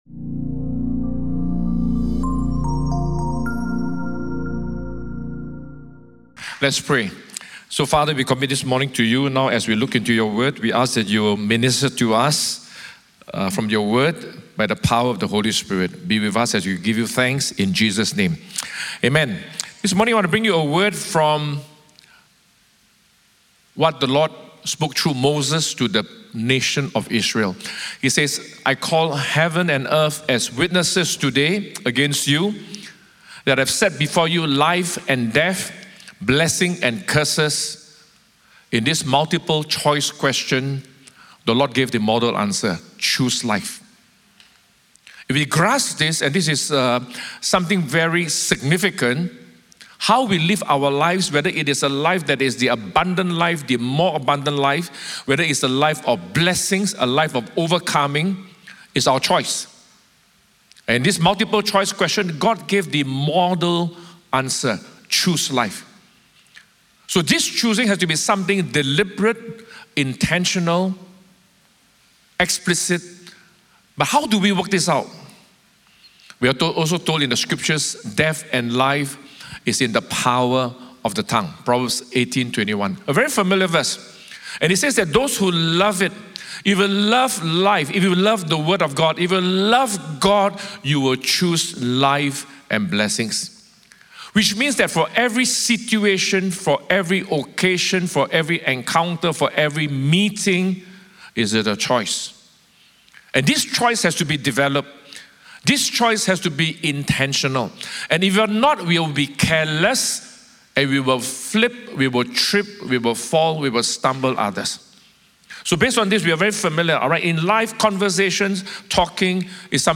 Weekly audio sermons from Cornerstone Community Church in Singapore